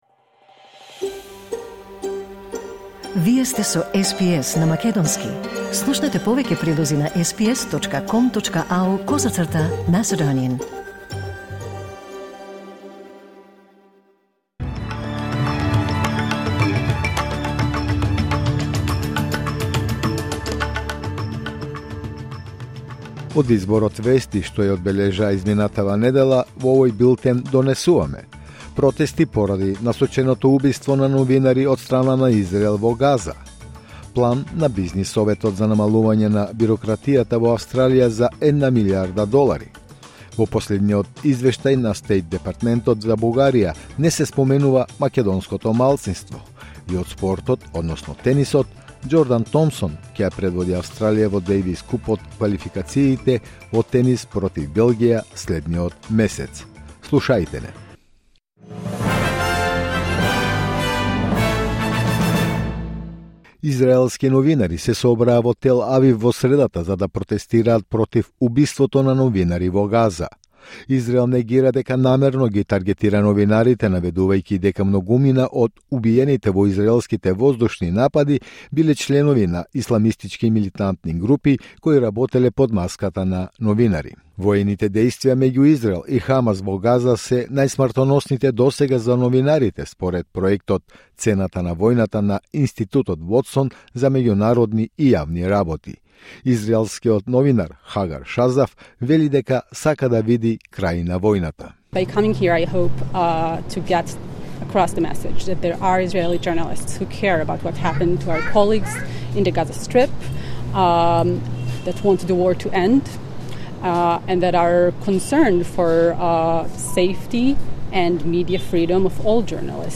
Weekly News Wrap in Macedonian 15 August 2025